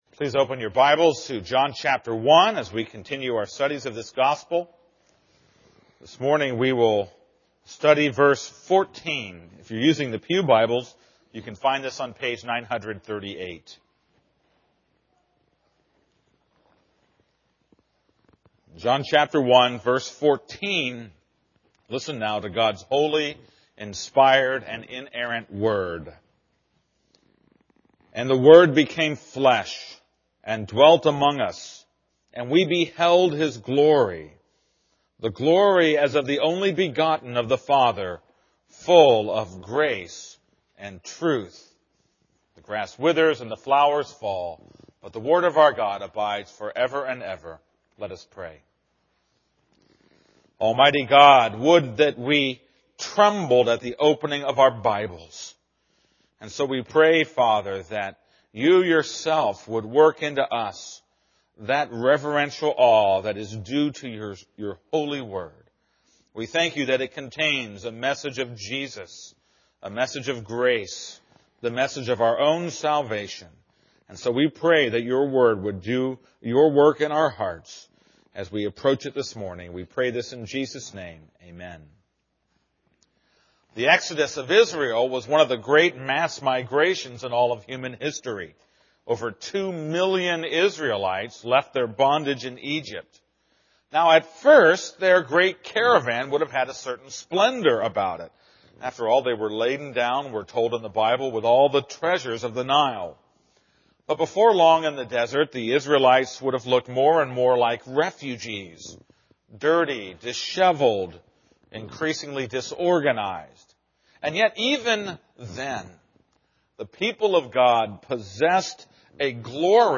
This is a sermon on John 1:14.